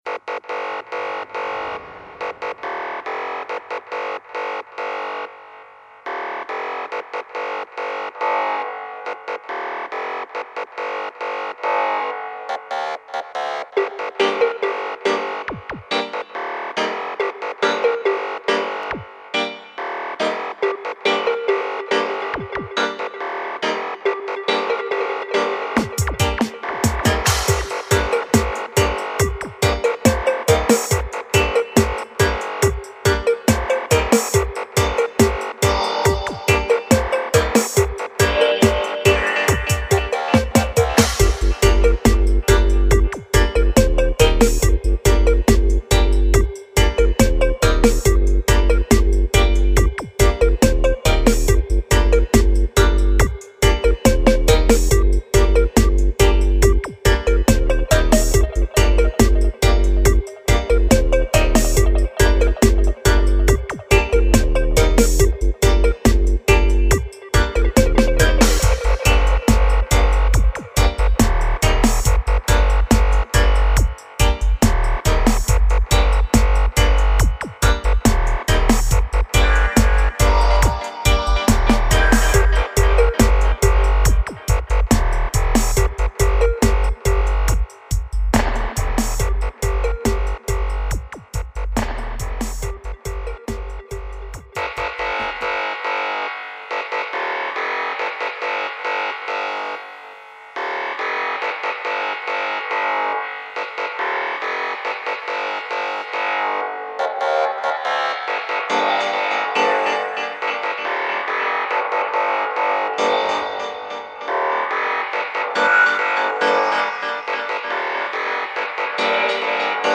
three cut Samples of the dubplate